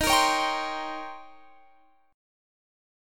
Eb13 Chord
Listen to Eb13 strummed